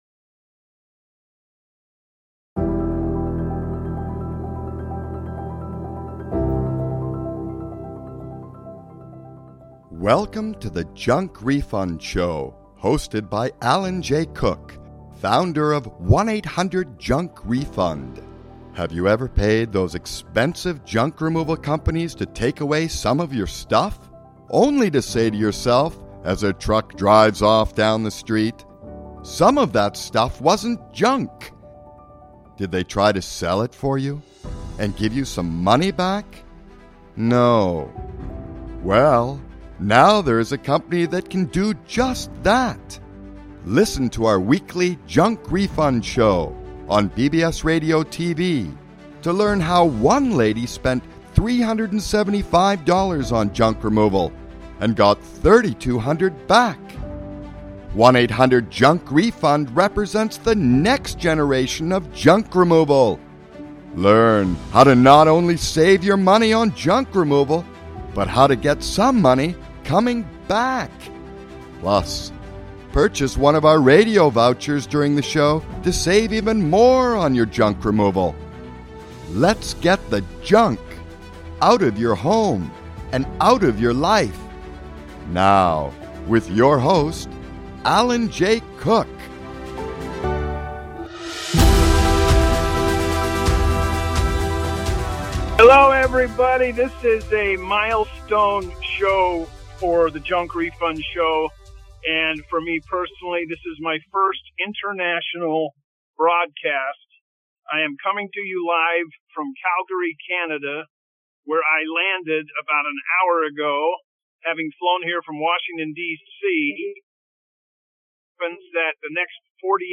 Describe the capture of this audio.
First International Broadcast from Calgary Canada.